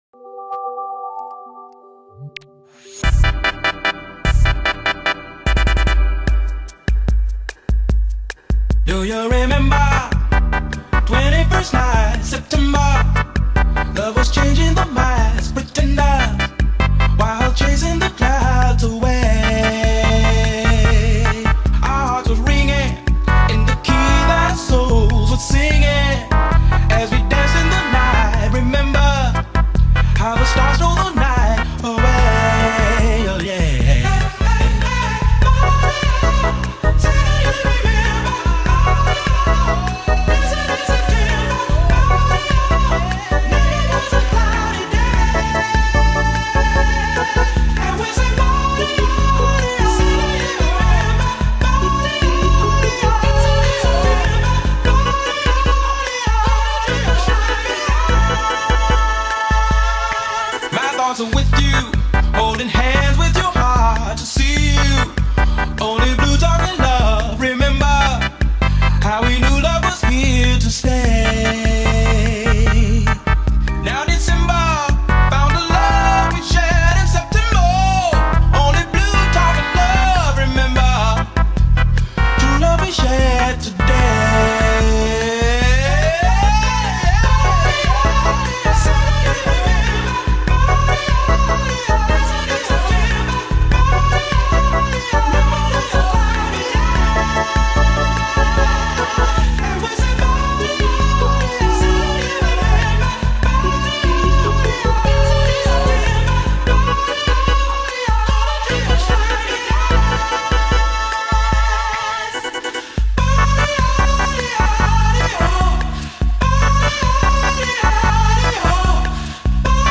mashup
BPM148
Audio QualityPerfect (Low Quality)